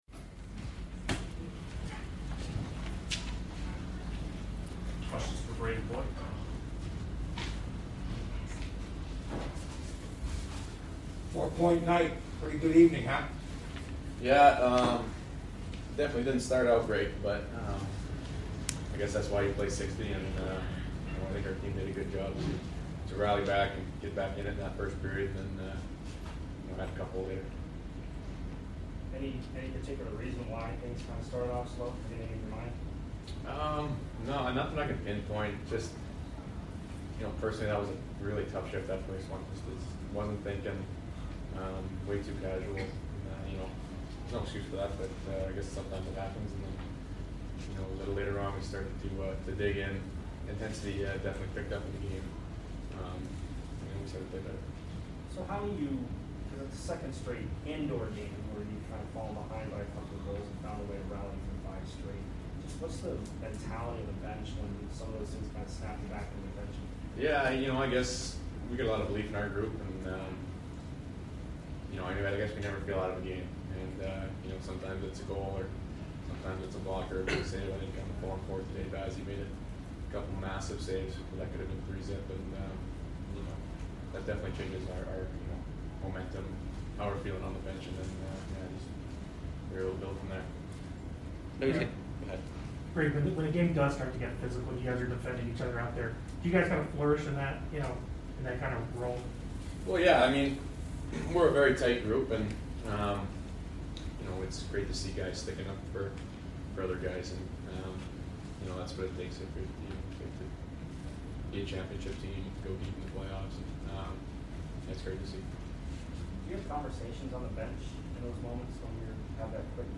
Brayden Point Post Game Vs Ottawa 3 - 1-22